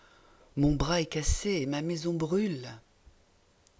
It sounds terrific.